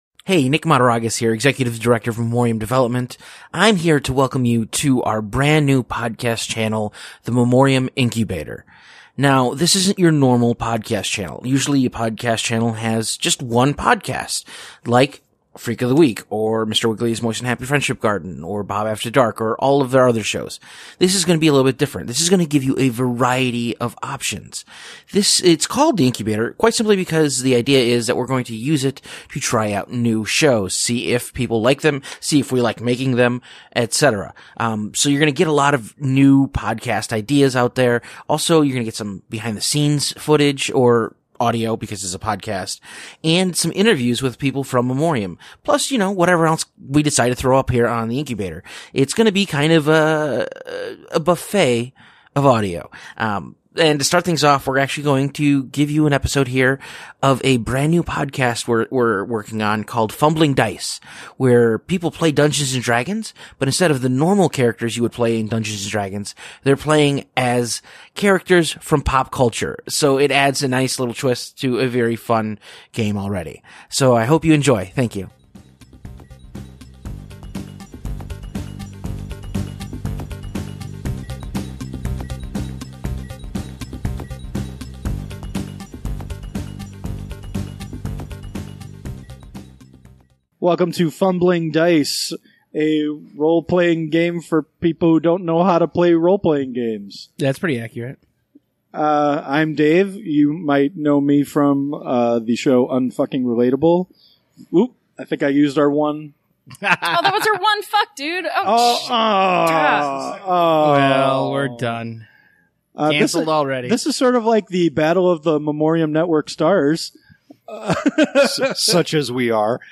Join four novice morons as they venture into the world of Dungeons and Dragons. Fumbling Dice is the D and D show where the usual suspects are replaced with characters from popular culture.